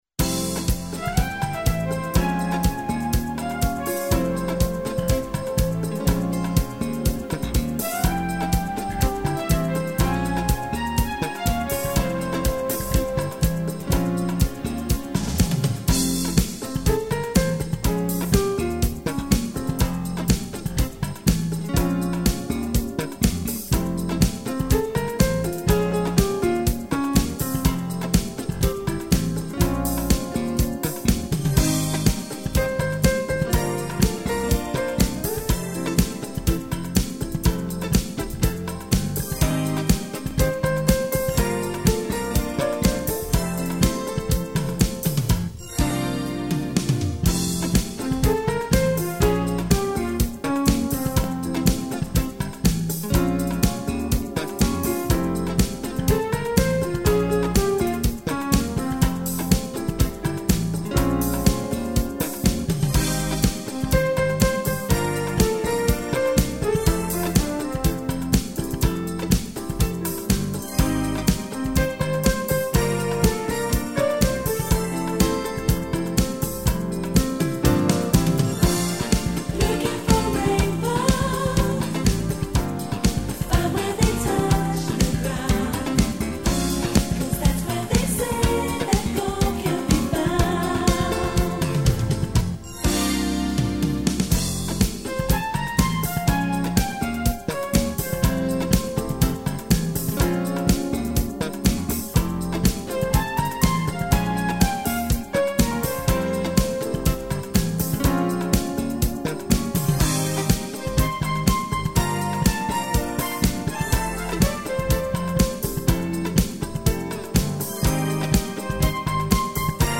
너무나 많은 감미로운 곡들중에서 한곡...